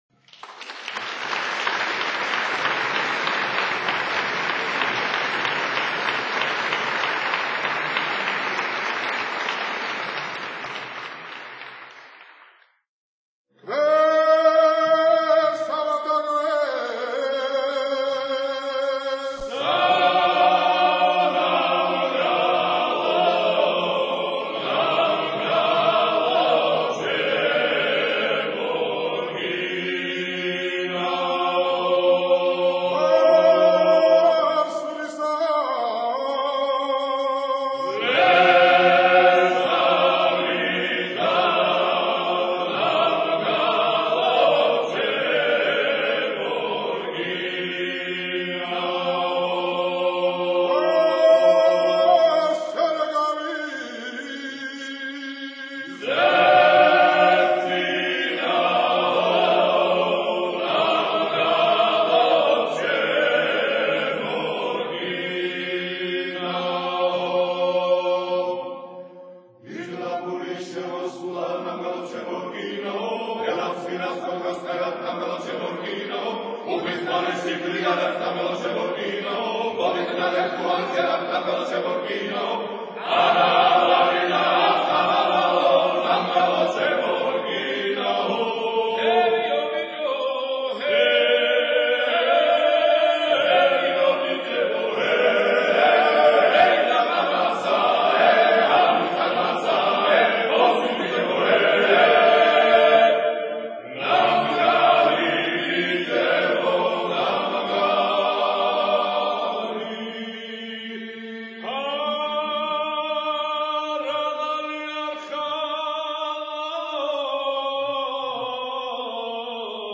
A typical Work song. which was sung at harvesting wheat.